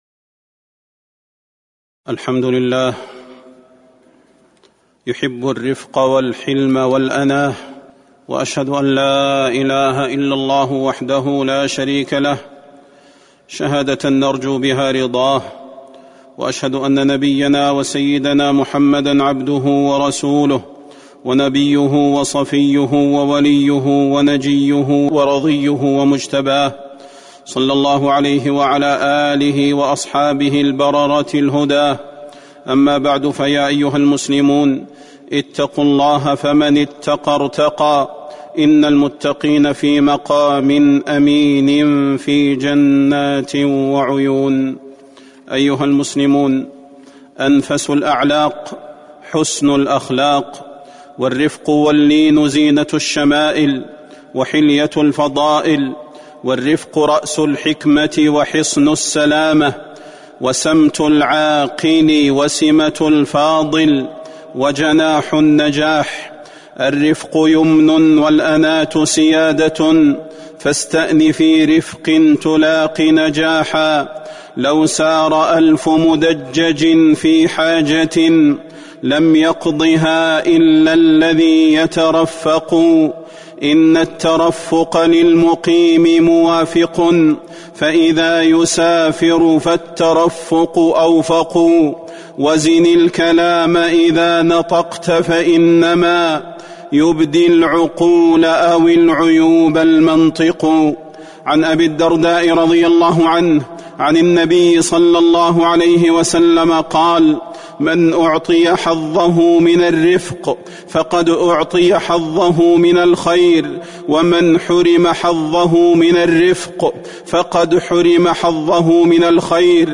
تاريخ النشر ١٨ جمادى الآخرة ١٤٤٣ هـ المكان: المسجد النبوي الشيخ: فضيلة الشيخ د. صلاح بن محمد البدير فضيلة الشيخ د. صلاح بن محمد البدير الرفق واللين